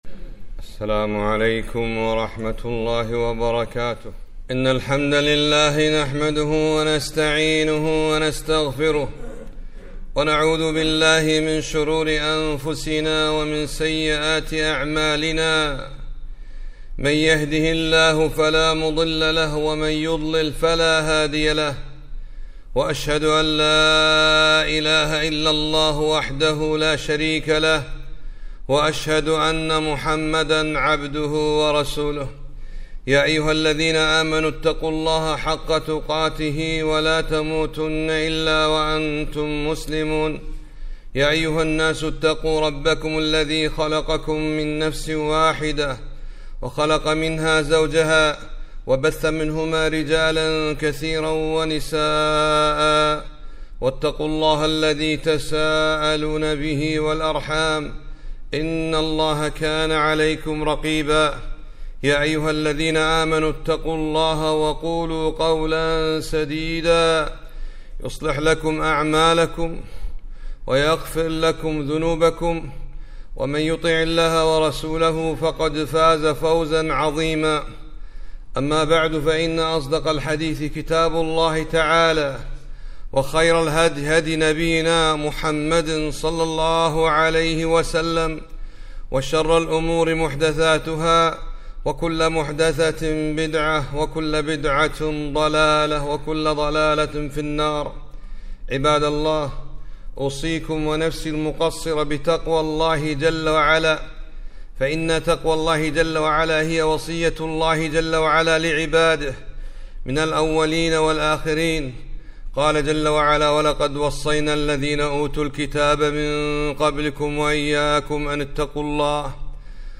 خطبة - (مُرُوا أَوْلادَكُمْ بالصَّلاةِ لِسَبْعٍ، واضْرِبُوهُمْ عليه)